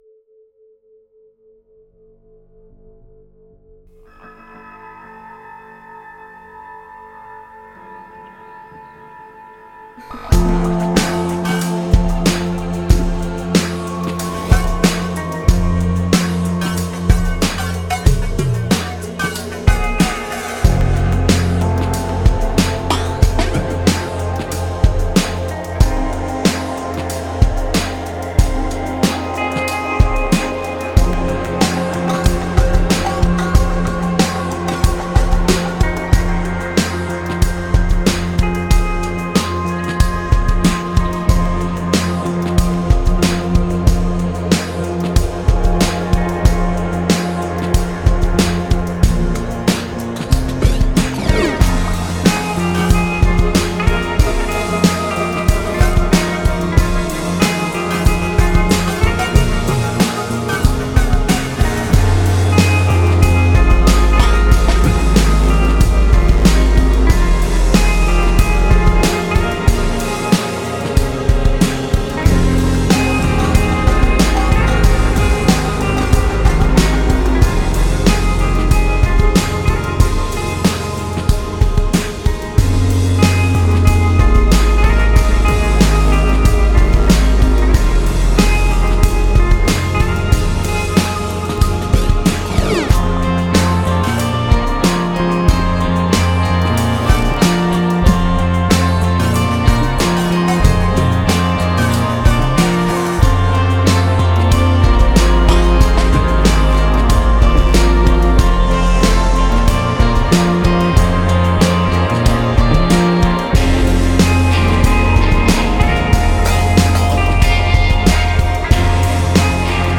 creating a dense and evolving soundscape.